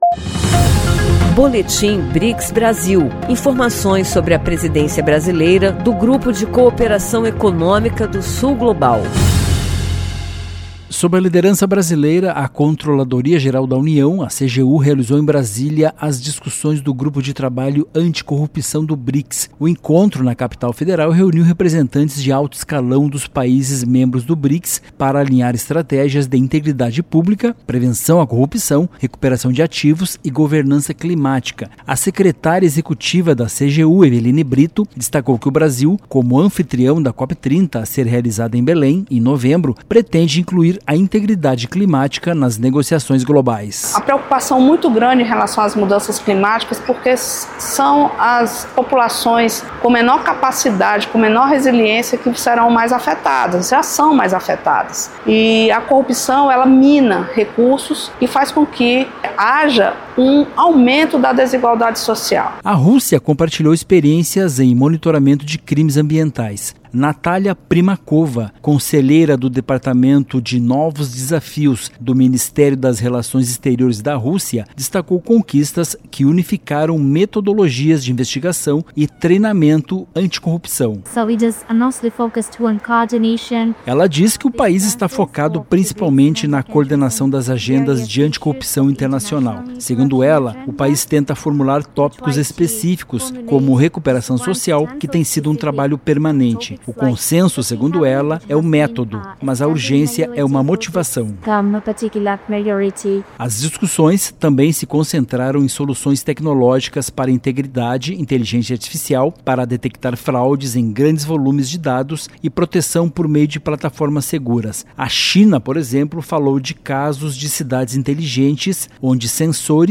De forma inédita, a sociedade civil do BRICS participará da Reunião de Sherpas em abril. Ouça a reportagem e saiba mais.